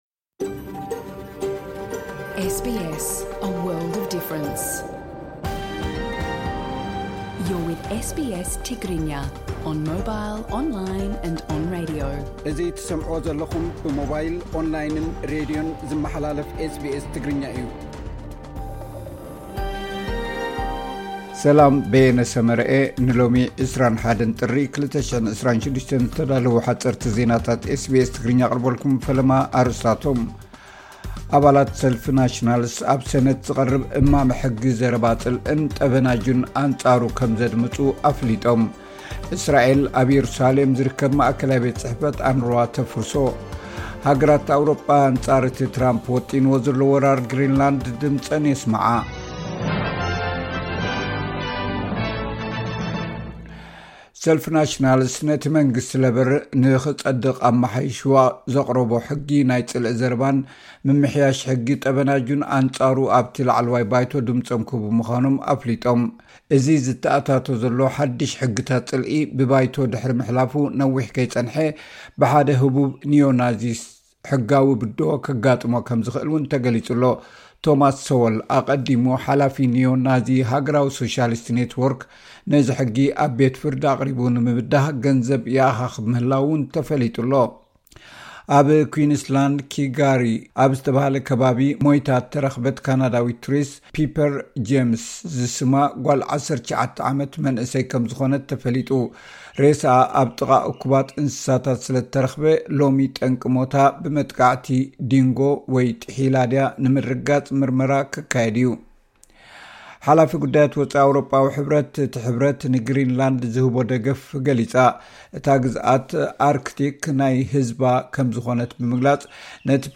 ወጥሪ ኣብ መንጎ ሕቡራት መንግስታት ኣመሪካን ሃገራት ኣውሮጳን ይውስኽ፡ ሓጺርቲ ዚናታት ኤስ ቢ ኤስ ትግርኛ (21 ጥሪ 2026)